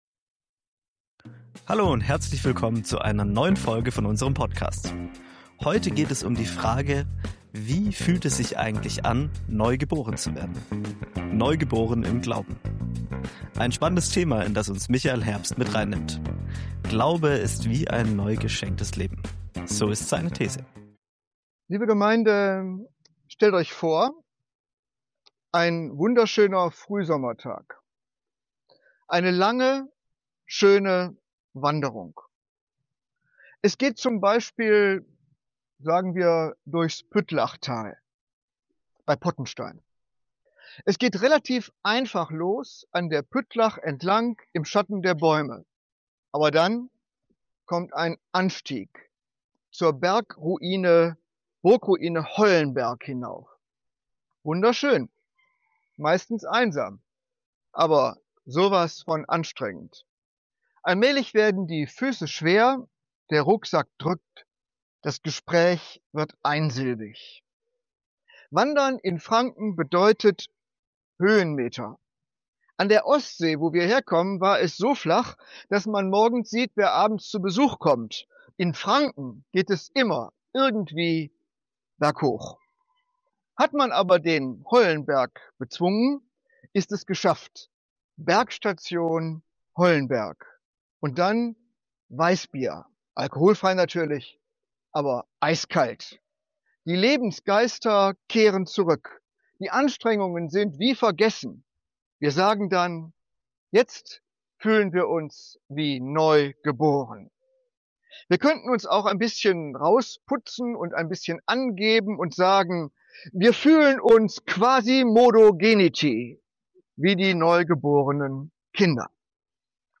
Glauben bedeutet nicht immer, alles zu sehen oder zu verstehen. In dieser Predigt